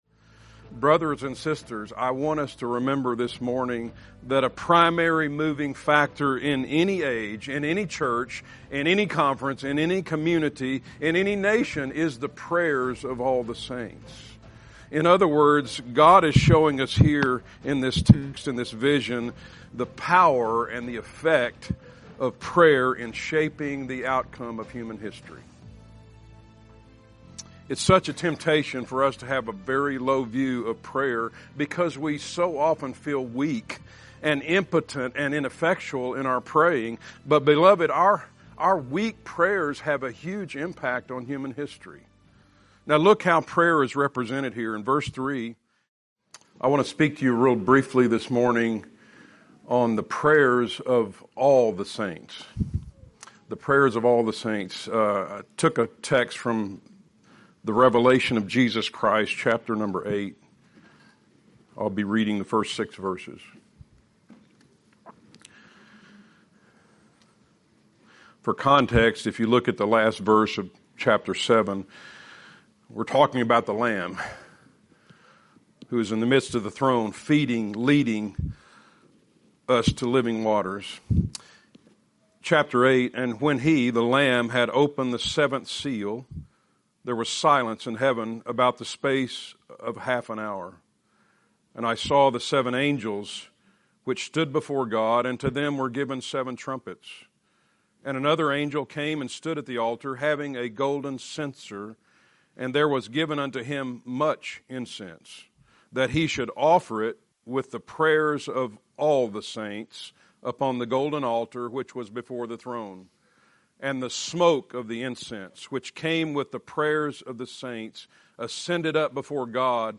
2023 Fellowship Conference